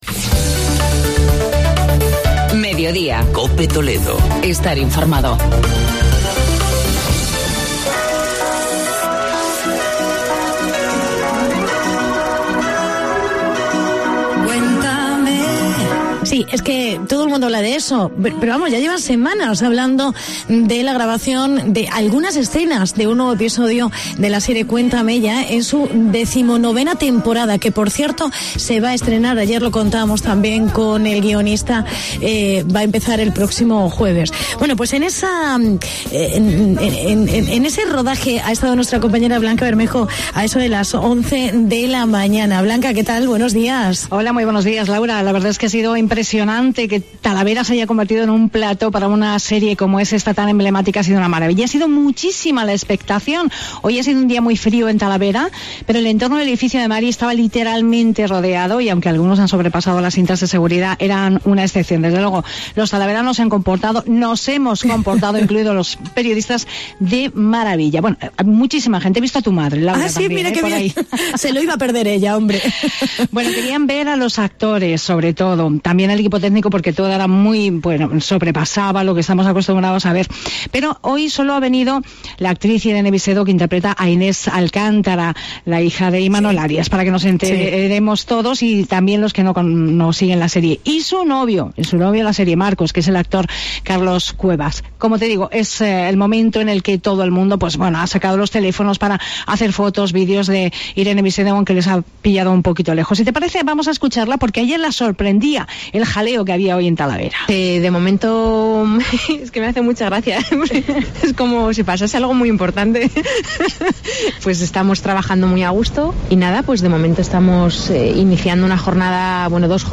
Hablamos con los actores y el público asistente